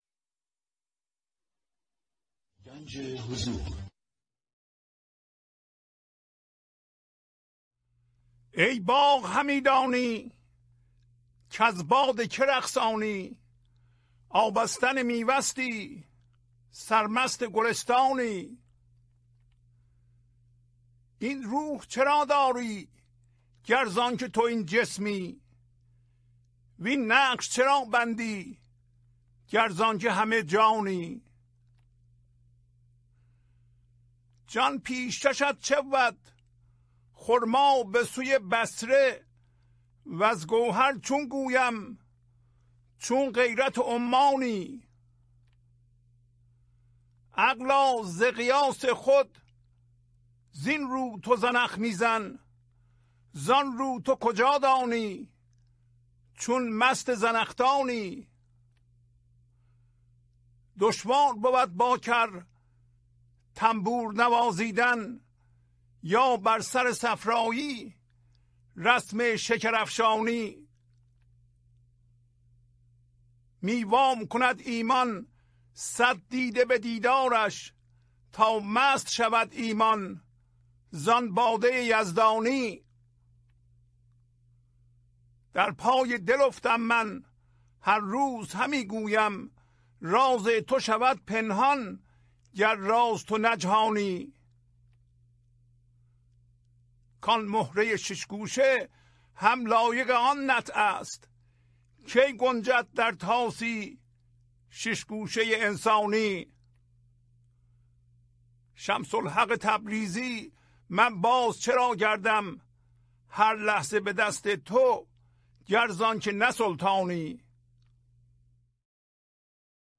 خوانش تمام ابیات این برنامه - فایل صوتی
1033-Poems-Voice.mp3